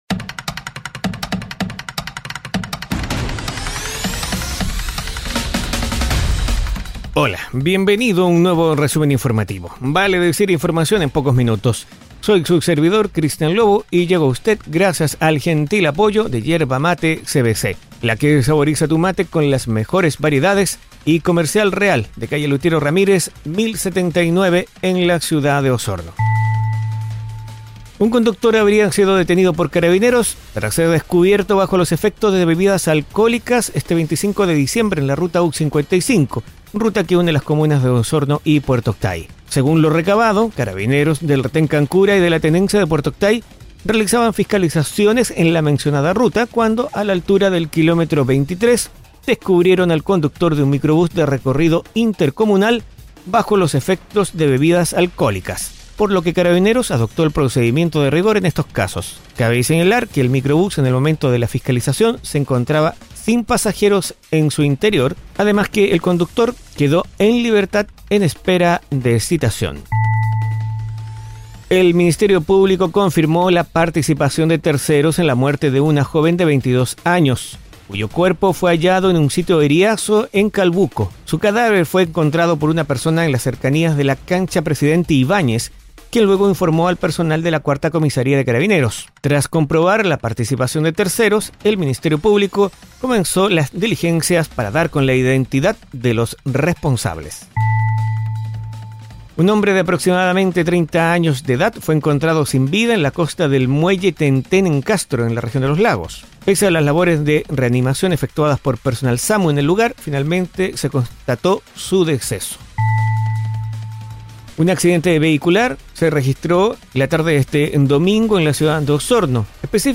Resumen Informativo 🎙 Podcast 26 de diciembre de 2022